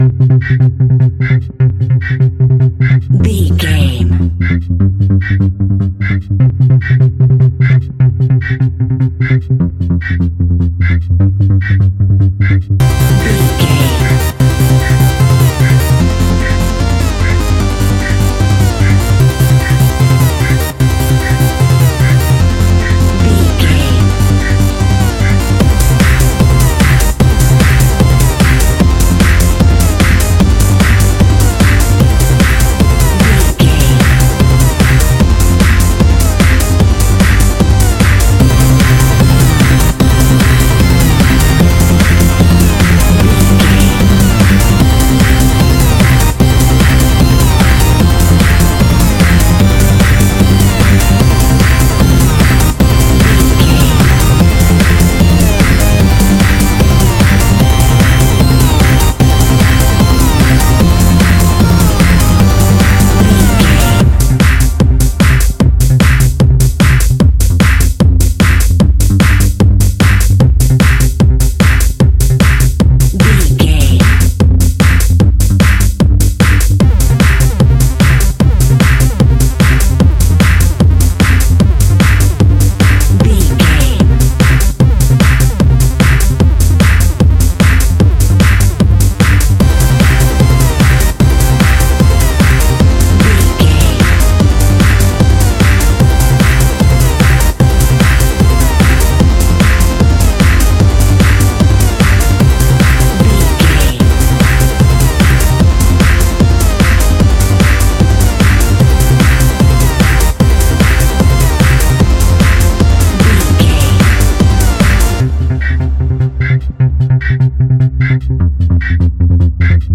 Aeolian/Minor
Fast
driving
energetic
hypnotic
industrial
drum machine
synthesiser
acid house
uptempo
synth drums
synth leads
synth bass